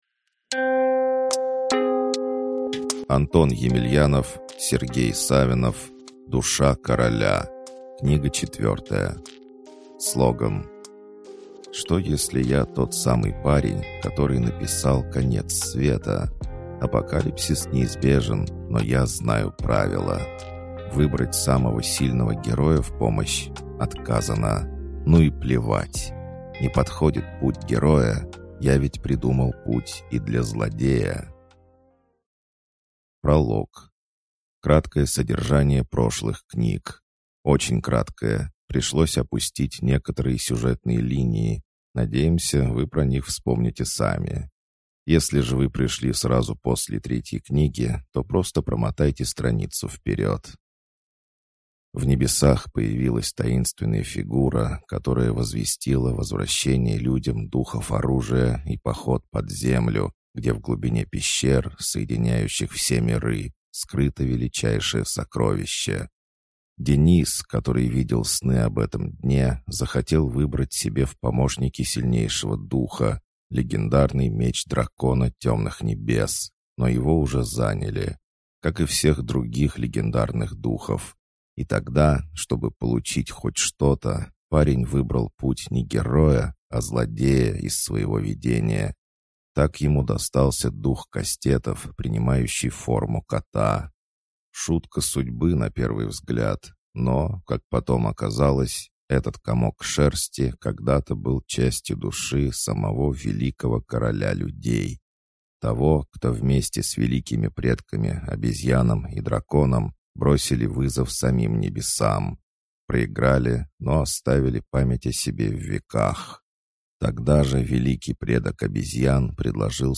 Аудиокнига Душа короля. Книга 4 | Библиотека аудиокниг